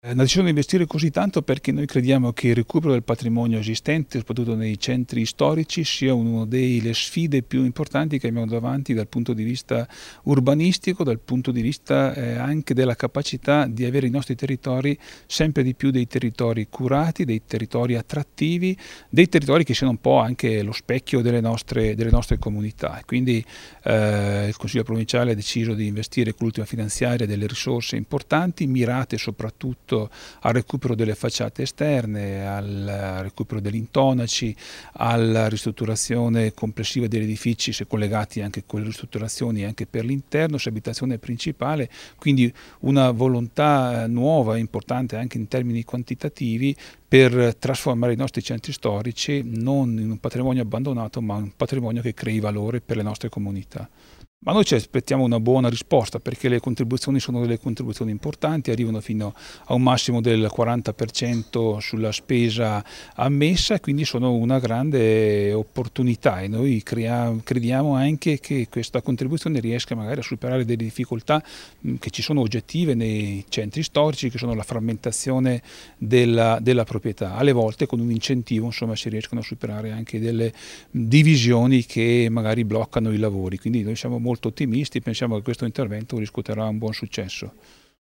Oggi, presso il Consorzio dei Comuni a Trento, con un appuntamento dedicato ai componenti delle Commissioni per la pianificazione territoriale e il paesaggio delle Comunità, è iniziato un percorso informativo e formativo che coinvolgerà anche gli amministratori locali e i professionisti del settore. In apertura è intervenuto l’assessore provinciale agli enti locali Carlo Daldoss.